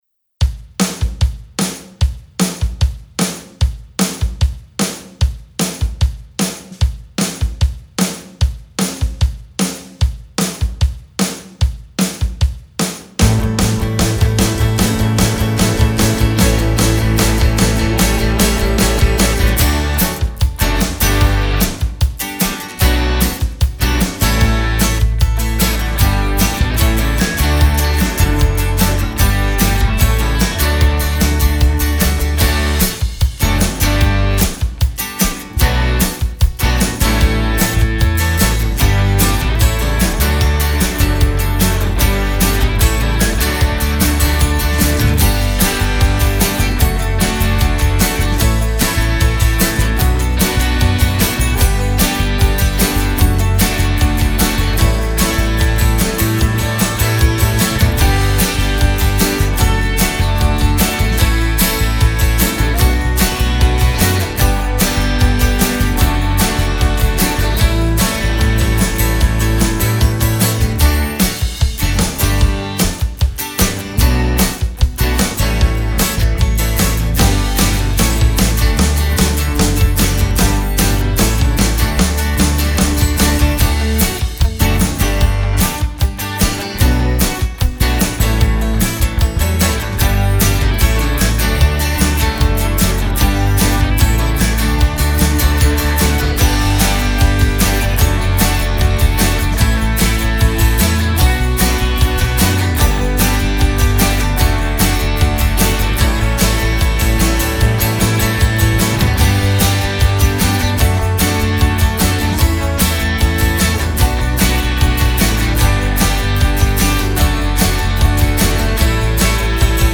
Karaokeversio
ilman laulua